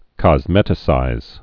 (kŏz-mĕtĭ-sīz)